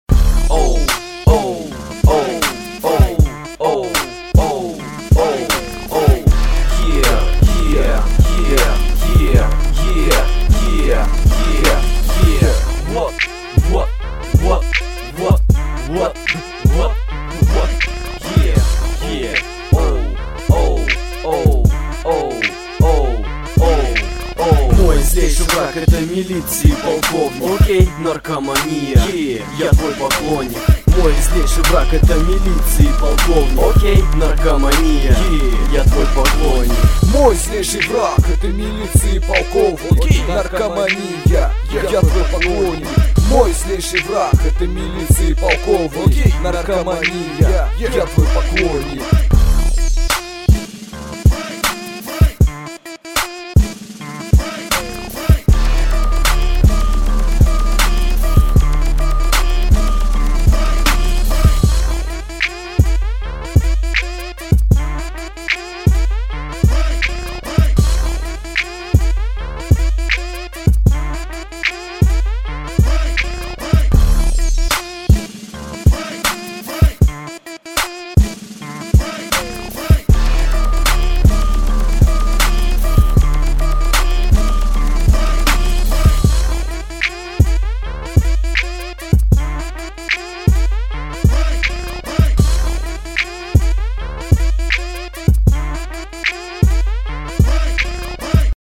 Форум » РЭПЧИК » ВАШИ ТРЕКИ » короче слушайте (???)
это демки
мне_нравиться_тока_у_тя_какойто_акцент_украинский...Ты_с_России???